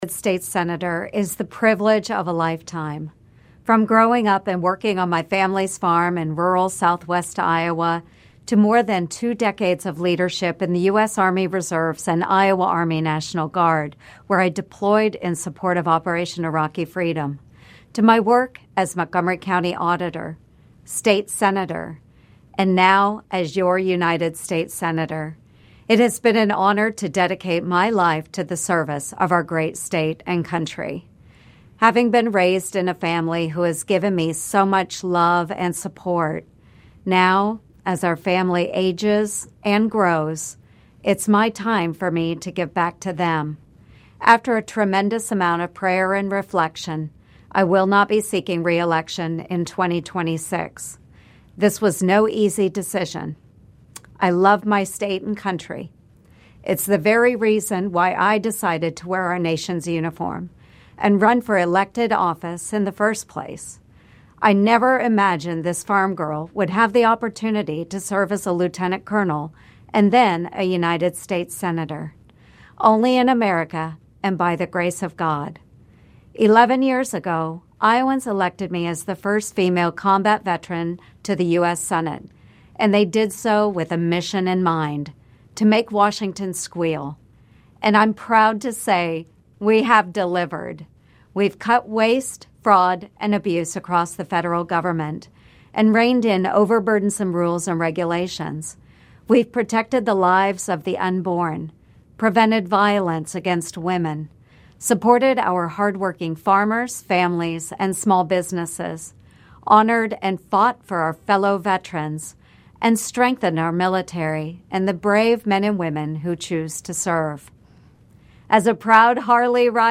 You can hear Joni Ernst’s official announcement below: